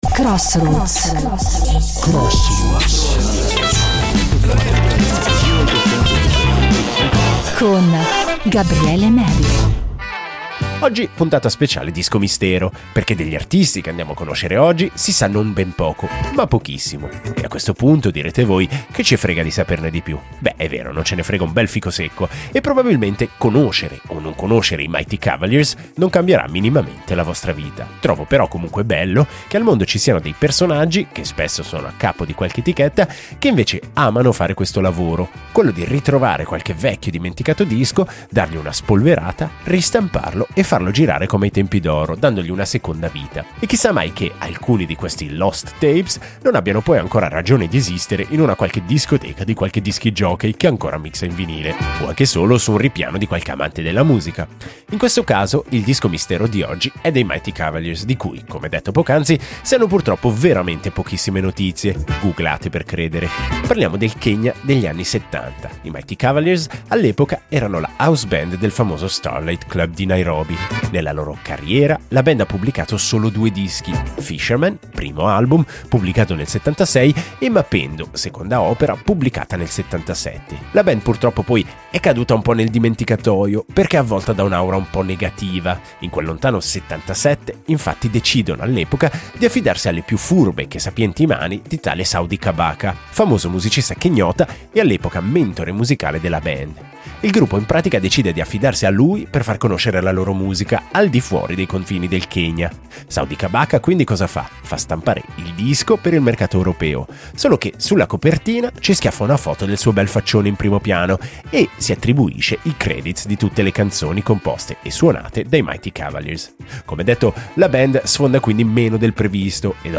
Un funk-afrobeat spolverato e tutto da scoprire!